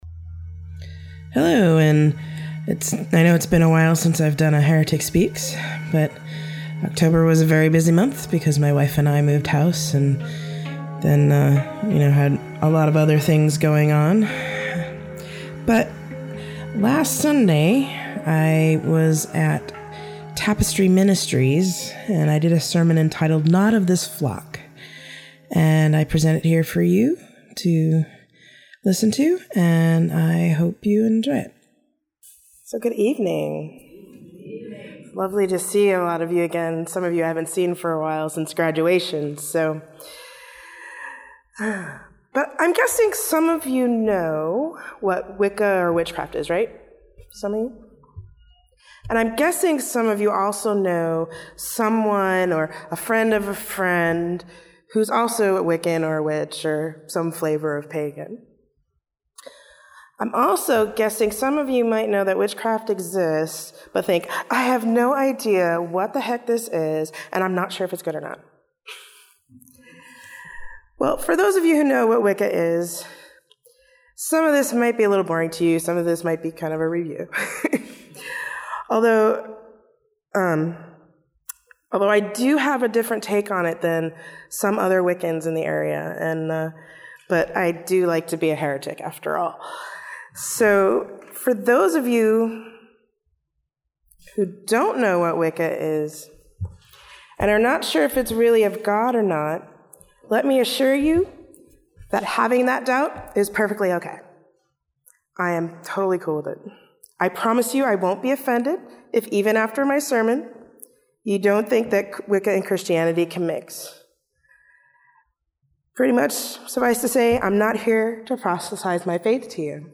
Sermon: Not of this Flock
The sermon was recorded on November 16, 2014 at the Tapestry Ministries service in the Chapel of the Great Commission at Pacific School of Religion. (And apologies for the weird sounding voice. I'm still getting over my cold!)